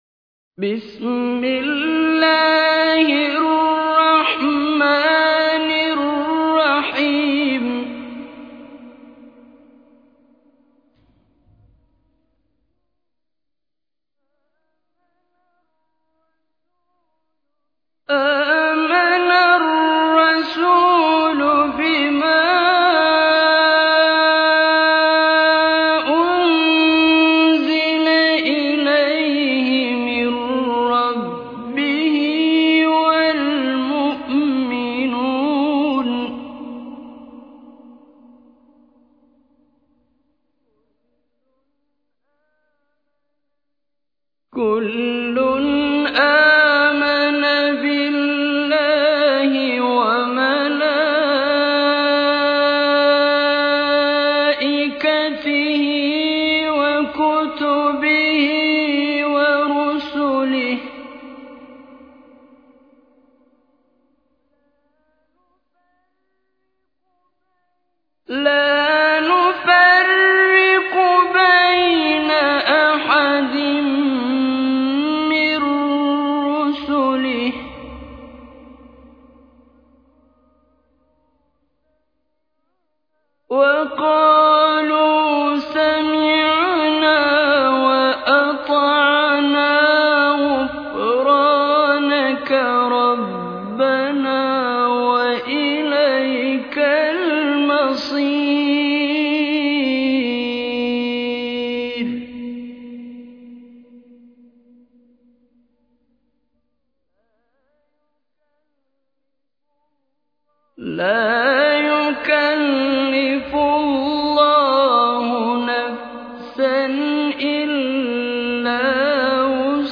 récitation mp3